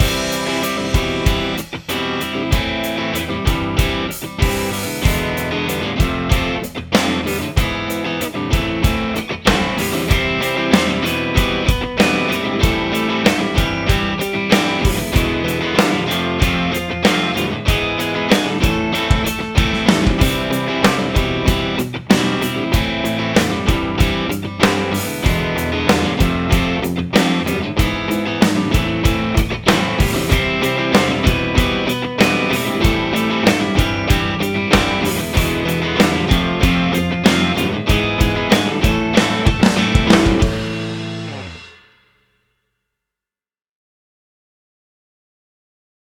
1959 Tele Set Vox AC30 / Celestion AlNiCo Blue Speakers - Jangle
59_AC30_Jangle.wav